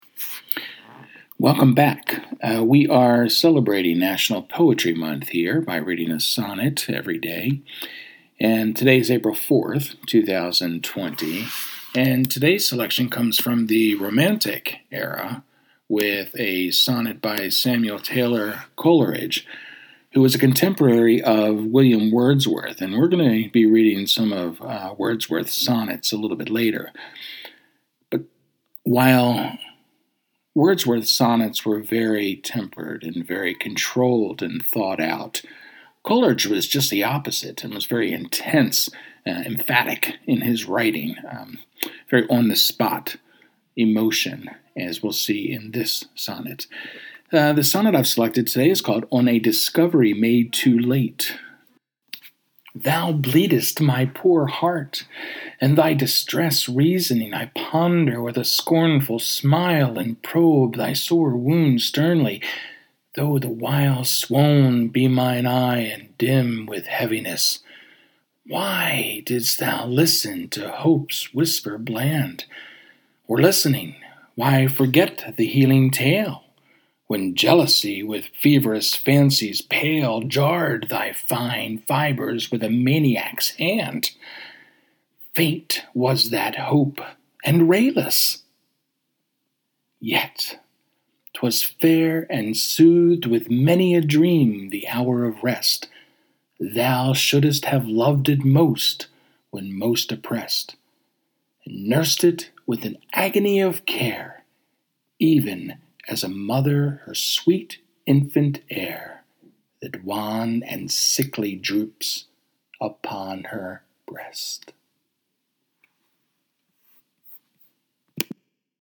Today, I am reading a sonnet by Samuel Taylor Coleridge, an British poet who lived during the Romantic Era at the turn of the 19th century.